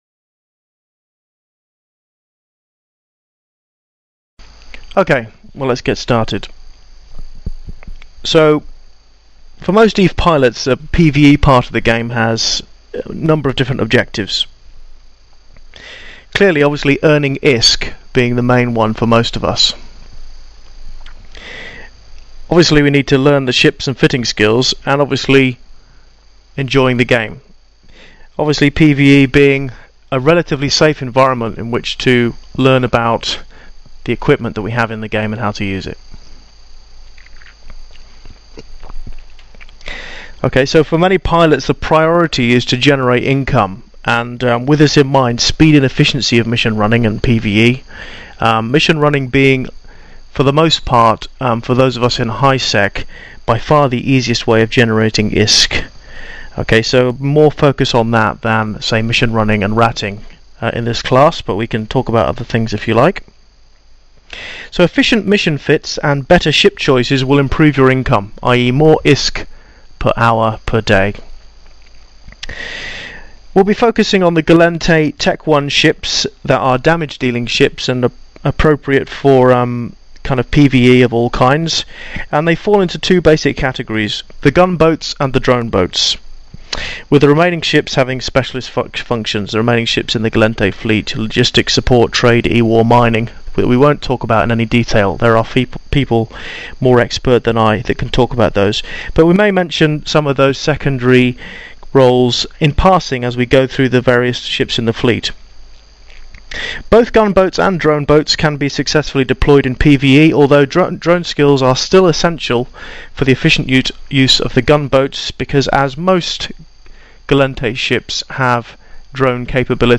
Gallente_PVE_ships_and_fittings_class.mp3